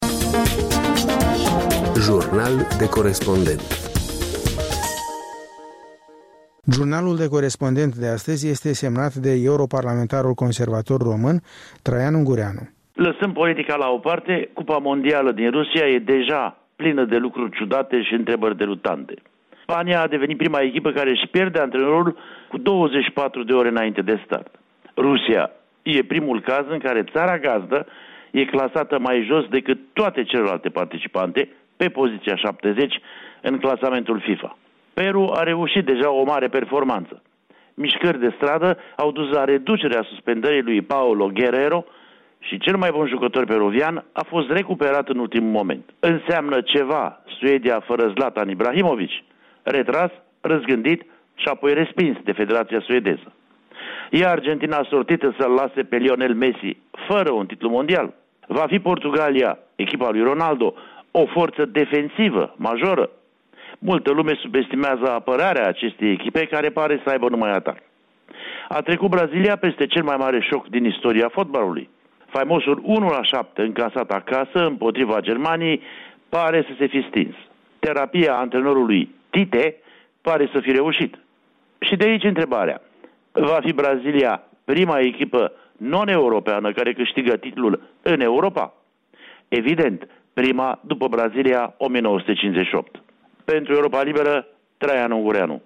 Jurnal de corespondent: Traian Ungureanu (Londra)